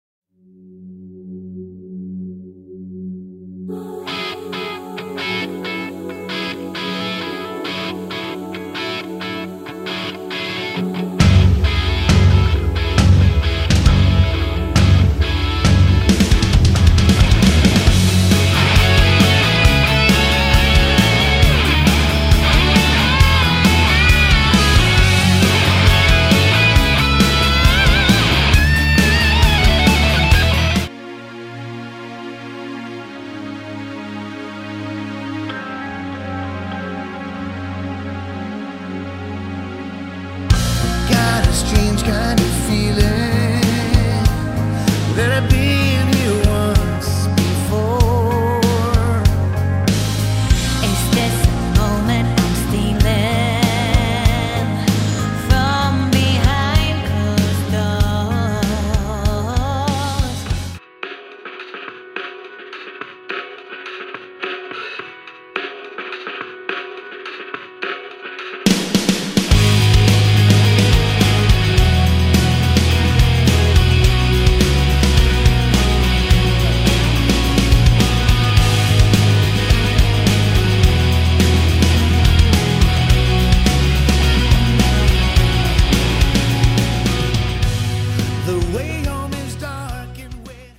hard core fans of melodic rock, this album delivers!
Lead Vocals
Drums, Keyboards, Backing Vocals
Guitars
Bass